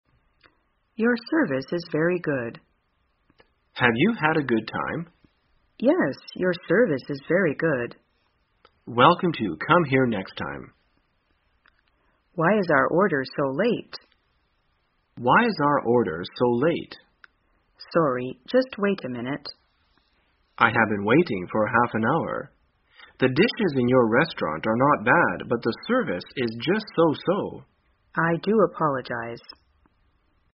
在线英语听力室生活口语天天说 第266期:怎样评价餐厅服务的听力文件下载,《生活口语天天说》栏目将日常生活中最常用到的口语句型进行收集和重点讲解。真人发音配字幕帮助英语爱好者们练习听力并进行口语跟读。